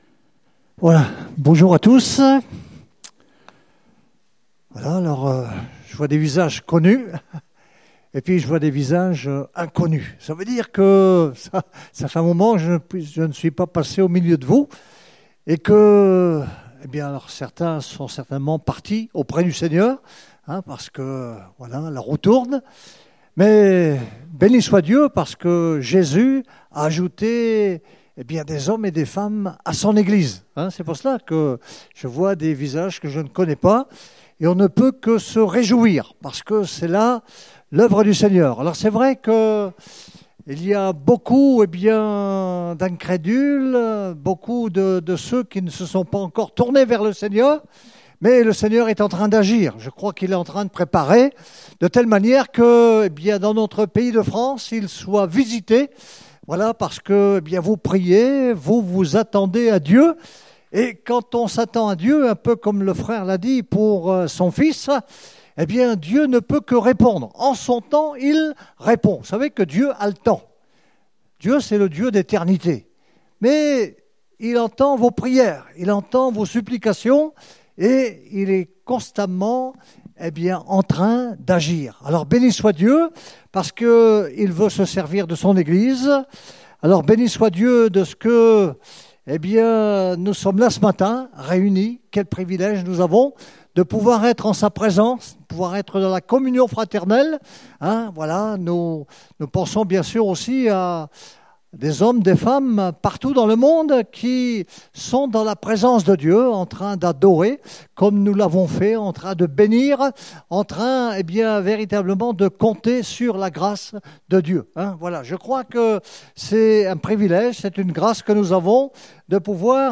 Date : 19 août 2018 (Culte Dominical)